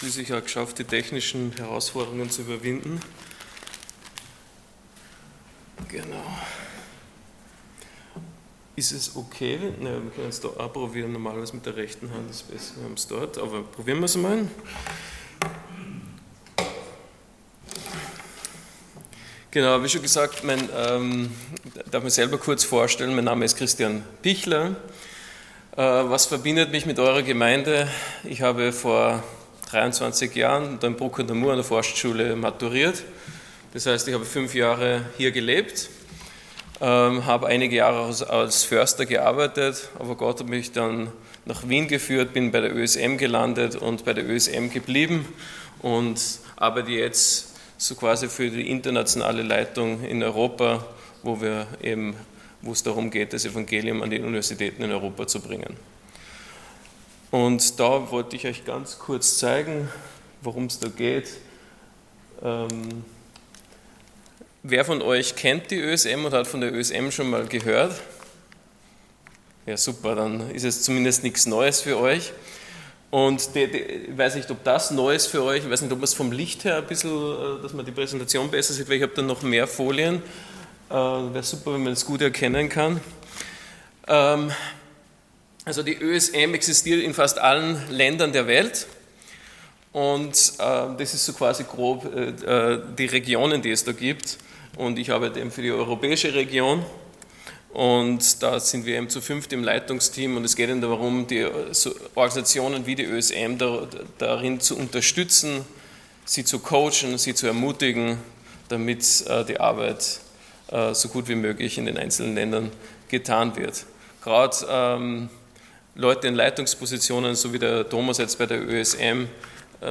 Matthäus 28 Dienstart: Sonntag Morgen Großer Auftrag Themen: Evangelisation , Mission « 15 Grüße und ganz viel Leben Vorsicht!